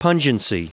Prononciation du mot pungency en anglais (fichier audio)
Prononciation du mot : pungency